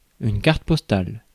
Ääntäminen
Synonyymit carte Ääntäminen France Tuntematon aksentti: IPA: /kaʁt pɔs.tal/ Haettu sana löytyi näillä lähdekielillä: ranska Käännös Ääninäyte Substantiivit 1. postcard US 2. letter card Suku: f .